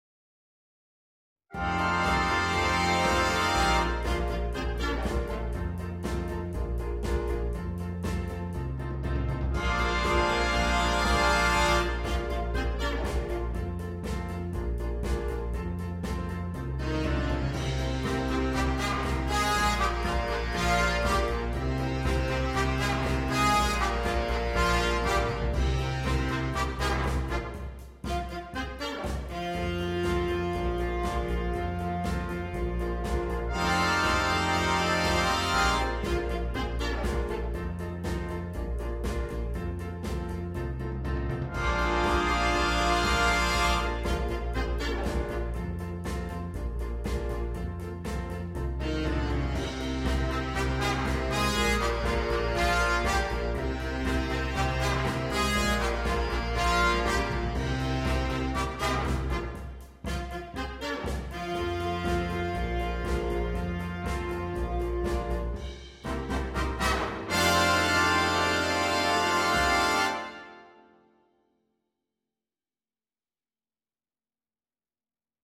для биг-бэнда